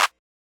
SB6 Clap (3).wav